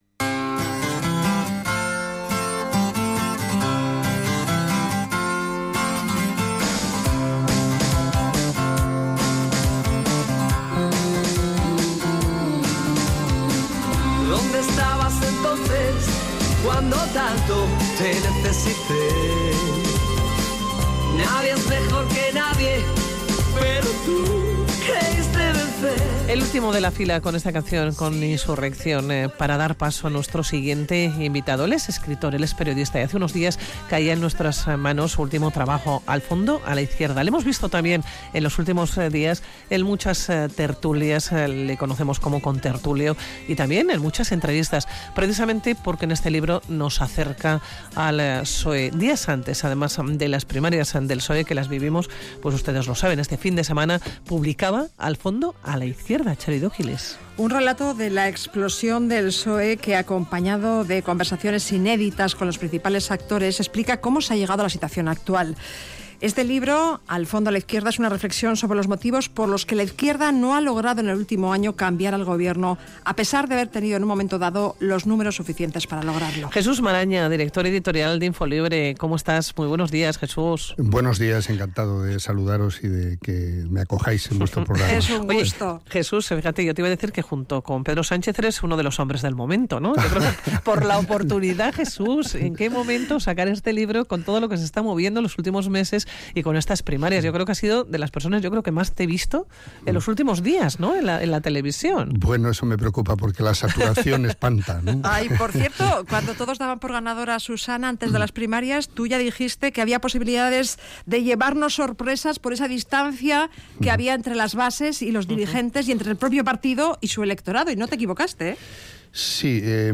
Audio: Radio Vitoria| Hablamos en El Mirador con el director editorial de Infolibre, Jesús Maraña, sobre su nuevo libro: "Al fondo a la izquierda".